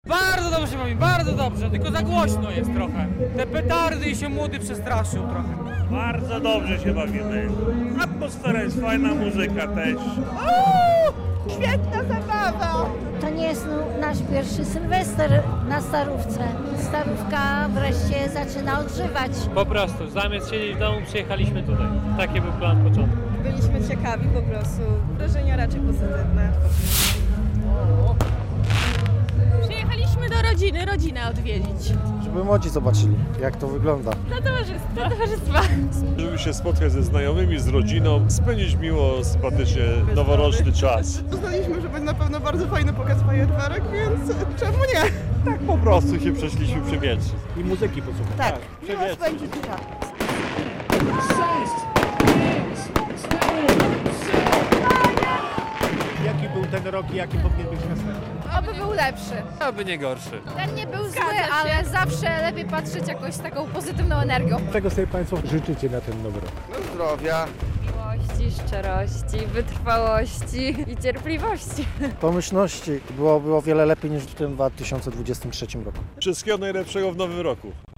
Mieszkańcy Łomży powitali nowy rok na Starym Rynku - relacja